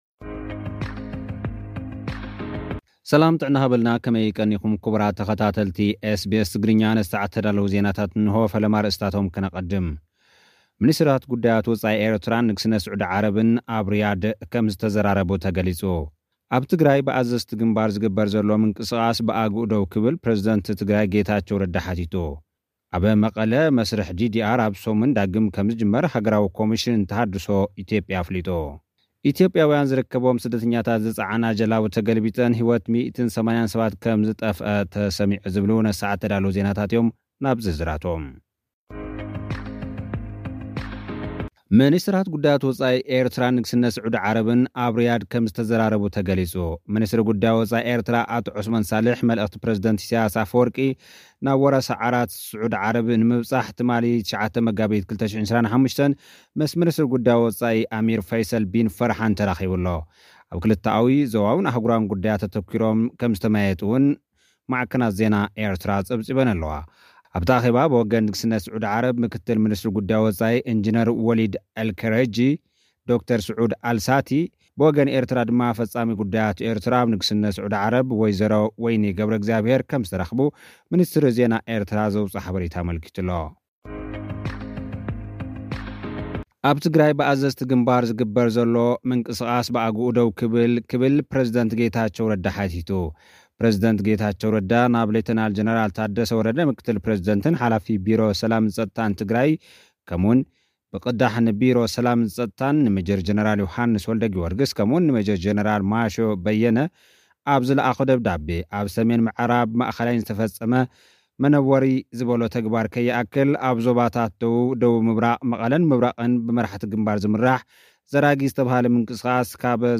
ልኡኽና ዝሰደደልና ጸብጻብ፡ ሚኒስተራት ጉዳያት ወጻኢ ኤርትራን ንግስነት ስዑዲ-ዓረብ ኣብ ርያድ ተመያዪጦም ። ኣብ ትግራይ ብኣዘዝቲ ግንባር ዝግበር ዘሎ ምንቕስቓስ ብኣግኡ ደው ክብል ፕረዚደንት ጌታቸው ረዳ ሓቲቱ። ኣብ ትግራይ መስርሕ (DDR) ኣብዚ ሰሙን ዳግም ከምዝጅመር ሃገራዊ ኮምሽን ተሃድሶ ኣፍሊጡ ። ኢትዮጵያውያን ዝርከብዎም ስደተኛታት ዝጸዓና ጃላቡ ተገልቢጠን ሃለዋት 180 ሰባት ጠፊኡ ።